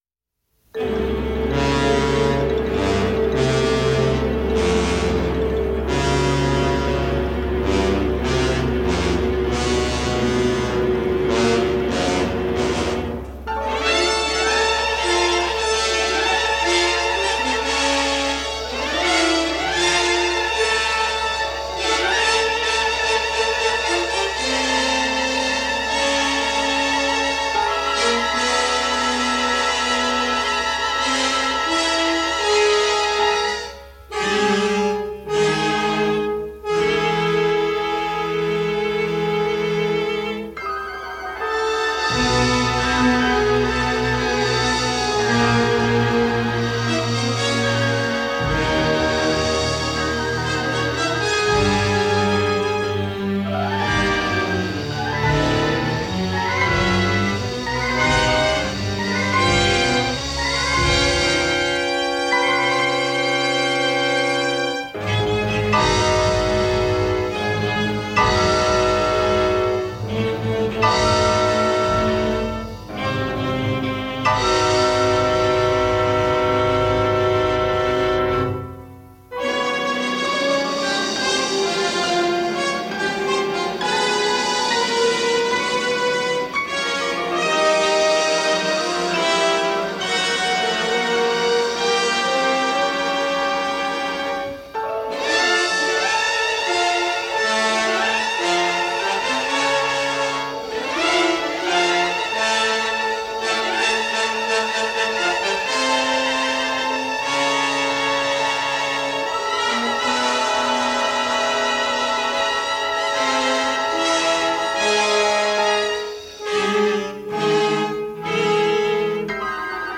Des scores assez massifs, mais pas écrasants pour autant.